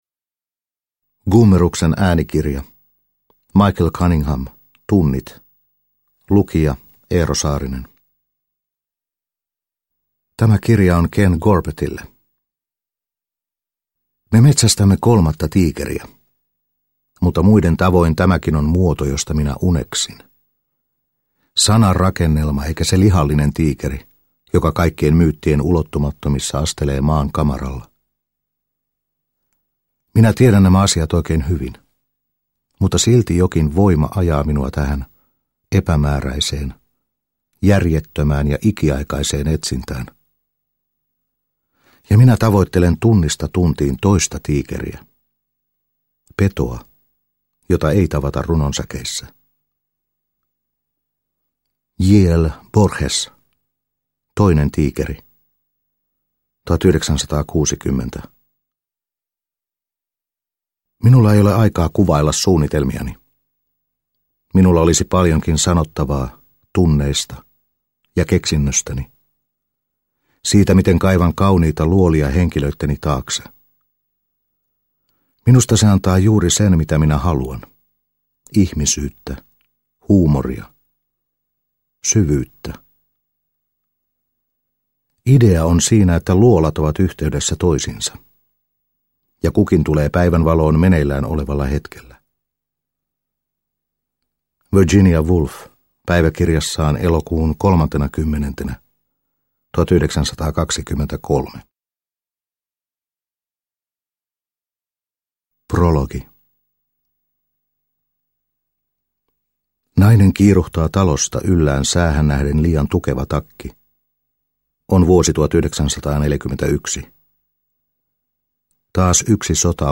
Tunnit – Ljudbok – Laddas ner